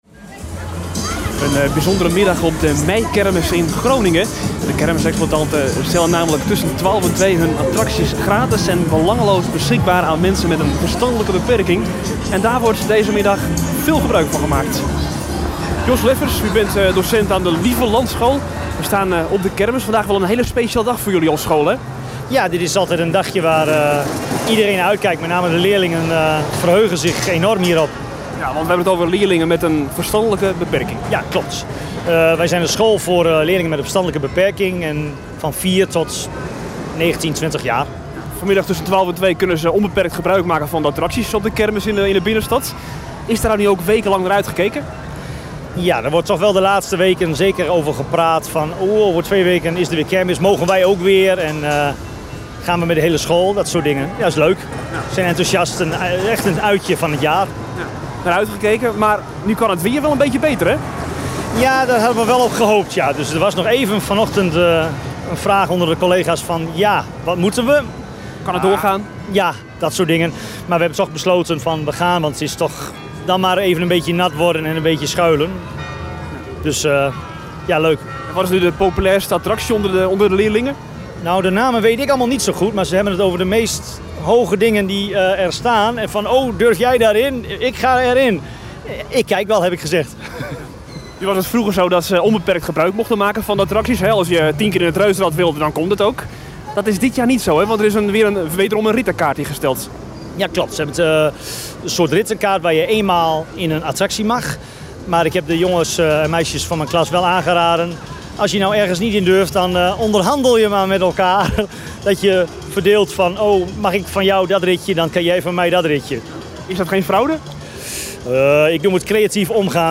Tientallen mensen met een verstandelijke beperking uit de stad bezoeken dinsdagmiddag de meikermis in de binnenstad. Het bezoek maakt deel uit van een jaarlijks terugkerende traditie.